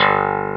CLAV2HRDG1.wav